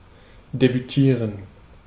Ääntäminen
IPA: /debyˈtiːʁən/ IPA: [debyˈtʰiːɐ̯n]